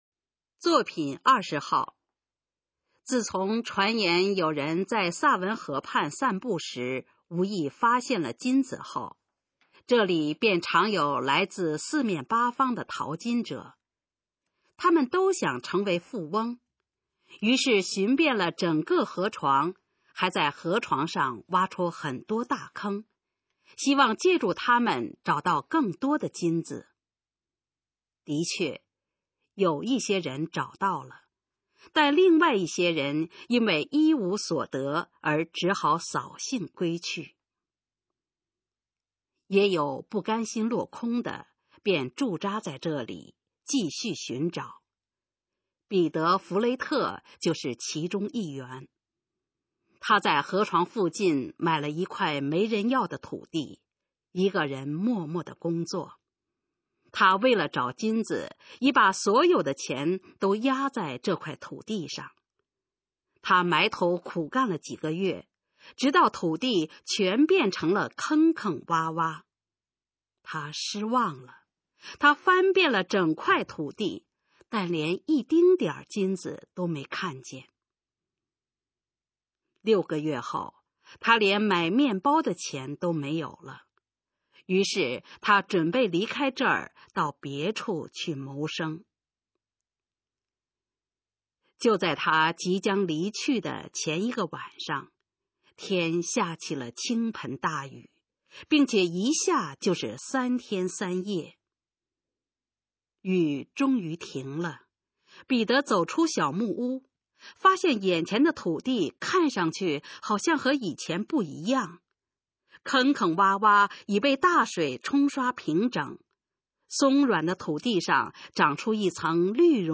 首页 视听 学说普通话 作品朗读（新大纲）
《金子》示范朗读_水平测试（等级考试）用60篇朗读作品范读